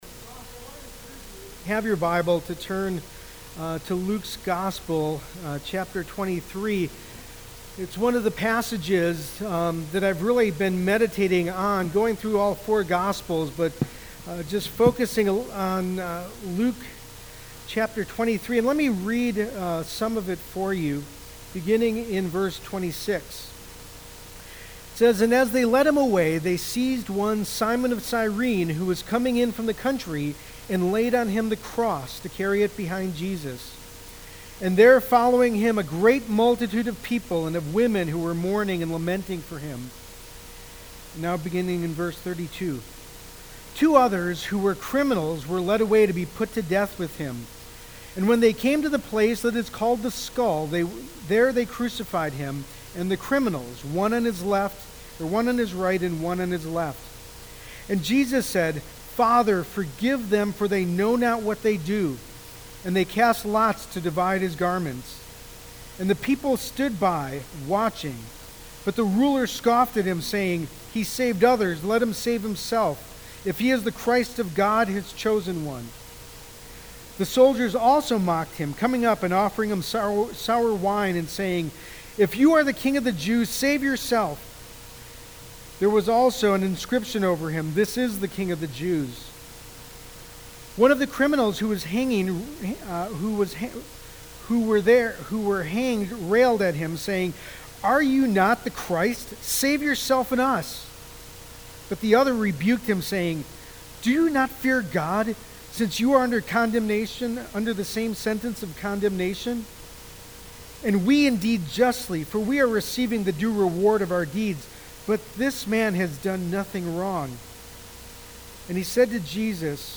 Good Friday Sermon